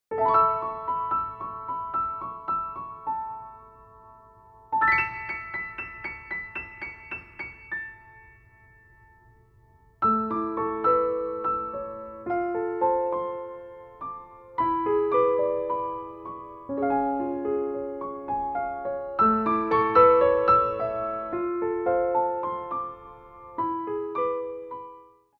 Port de Bras 2
4/4 (8x8)